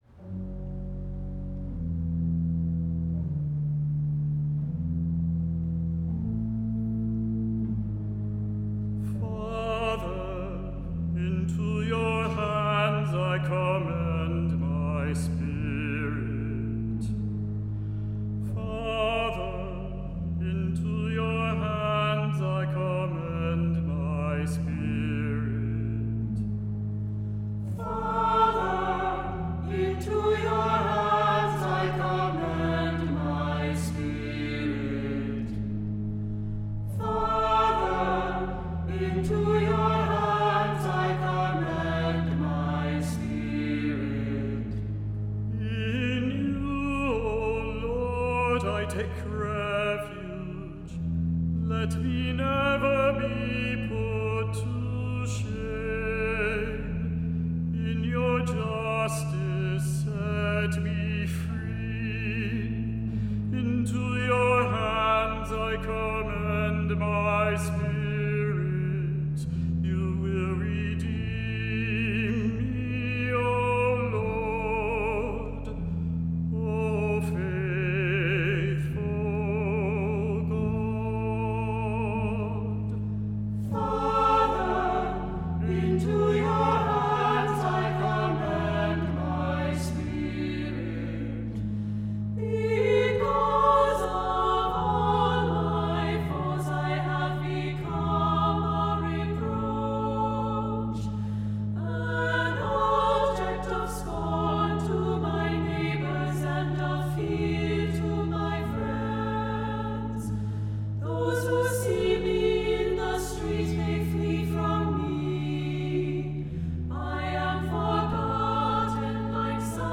Voicing: "SATB","Cantor","Assembly"